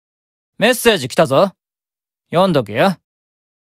Mammon_Chat_Notification_(NB)_Voice.ogg